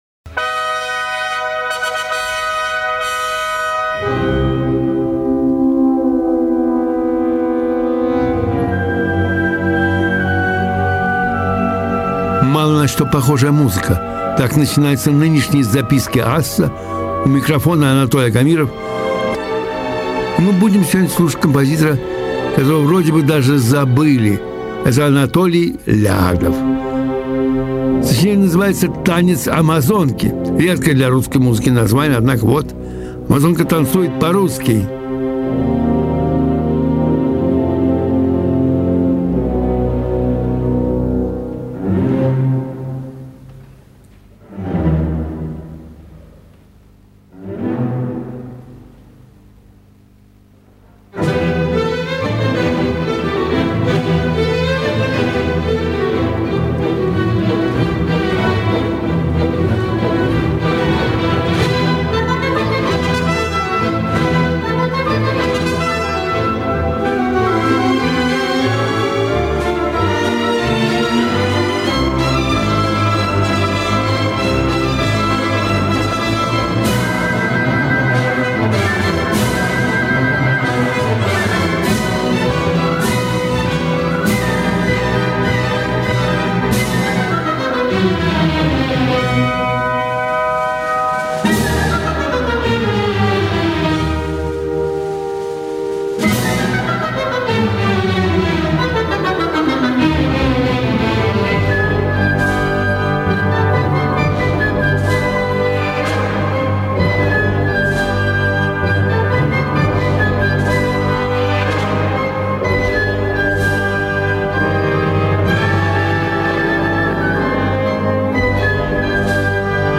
Это архивная запись,сделанная в 2005 году по случаю 150 - летия композитора.